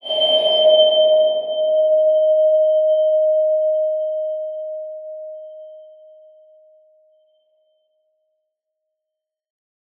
X_BasicBells-D#3-ff.wav